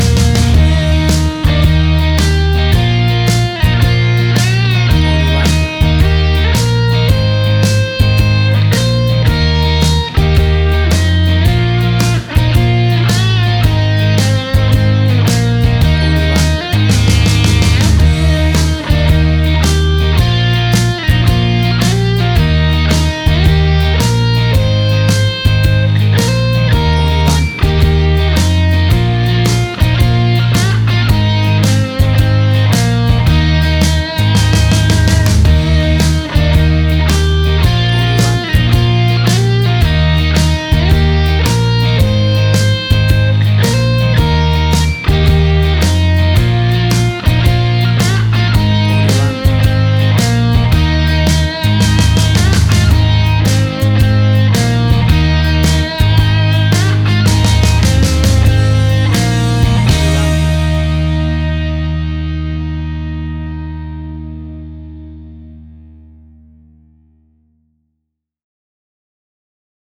A big and powerful rocking version
Tempo (BPM): 110